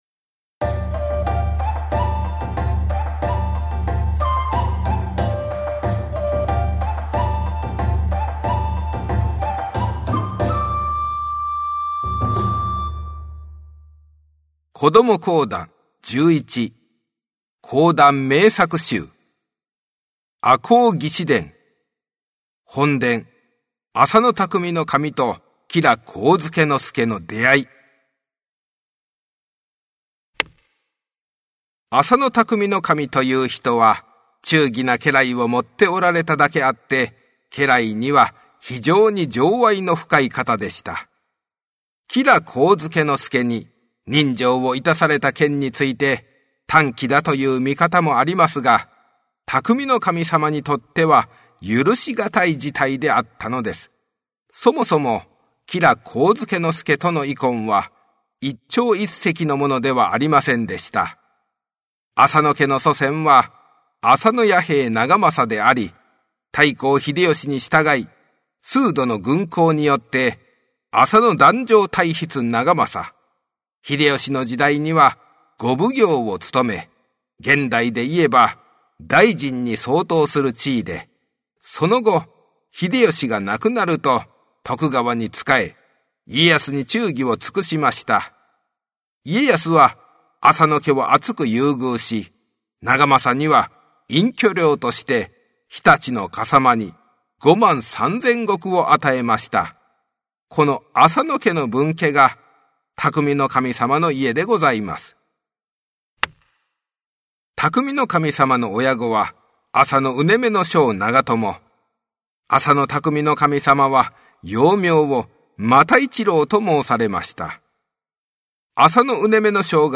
お子様向け短めの講談を楽しんでください♪